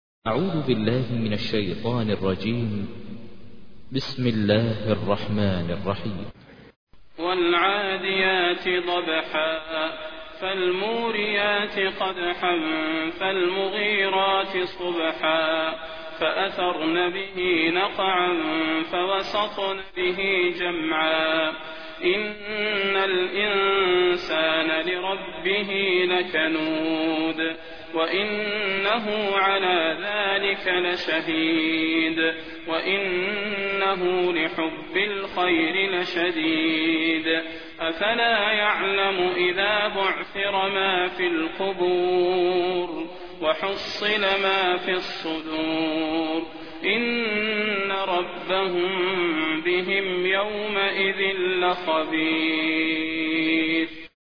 سورة العاديات / القارئ ماهر المعيقلي / القرآن الكريم / موقع يا حسين